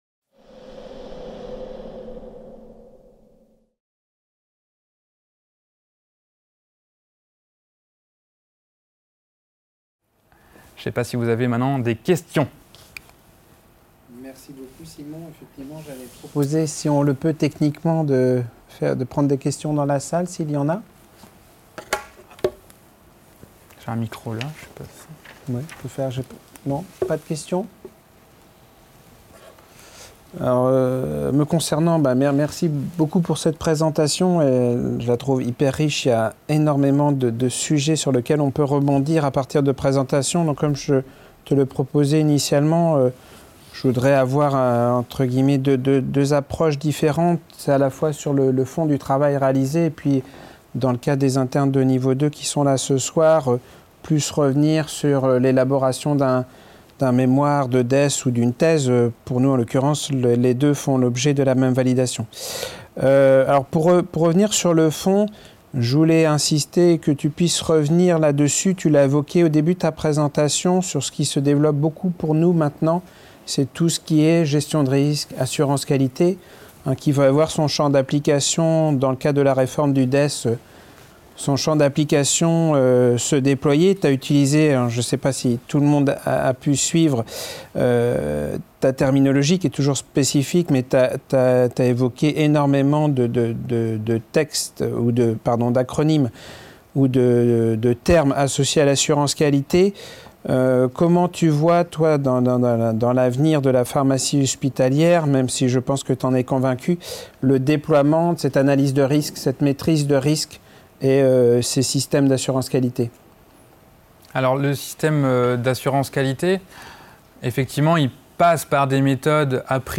2019 Thèse en poche | 01 B. Chambre des erreurs : d’une réalité régionale au parcours immersif à 360° - table ronde | Canal U